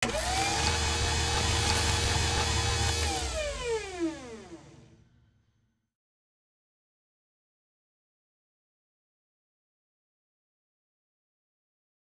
WeaponsRecharge.wav